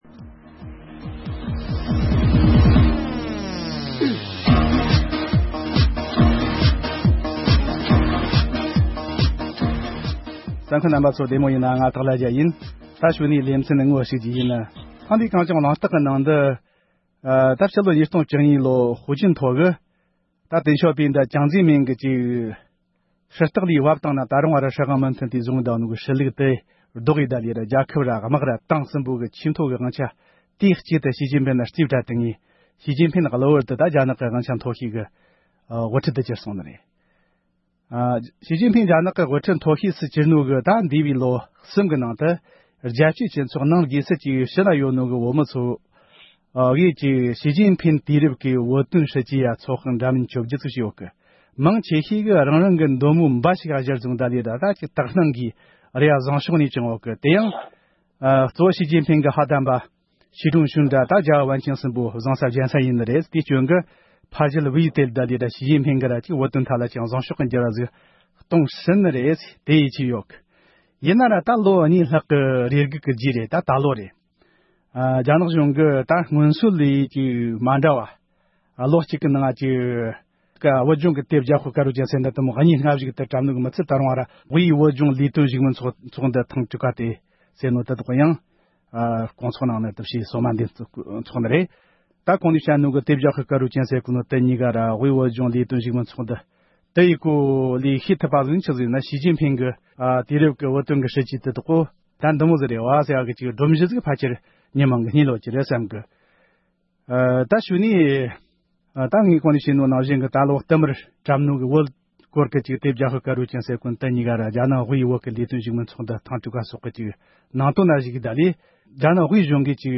ང་ཚོས་དེ་ལ་བརྡ་ལན་ཞིག་སྤྲོད་དགོས་མིན་དང་རྣམ་འགྱུར་ཅི་ཞིག་འཛིན་དགོས་པ་སོགས་ཀྱི་ཐད་བགྲོ་གླེང་བྱེད་རྒྱུ་ཡིན།